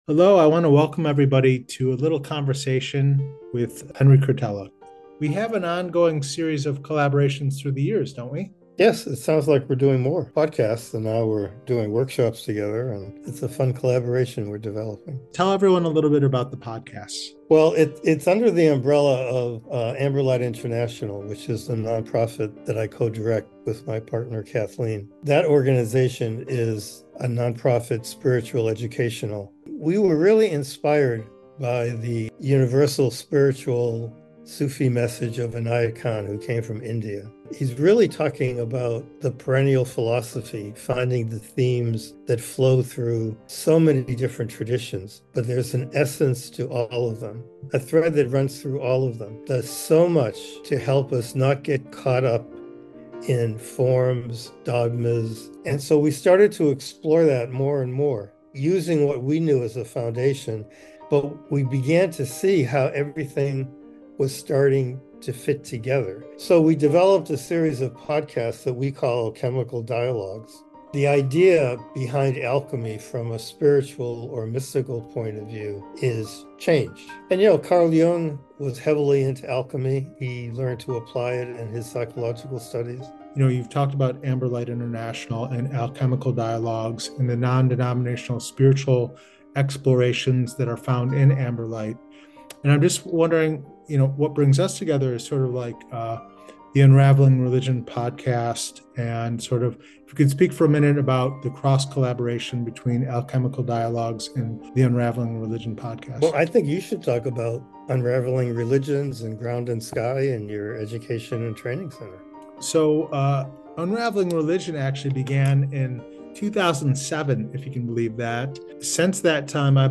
Listen to this collaboration to build healthy community with two old friends who share a common vision and passion to improve the quality of life for others.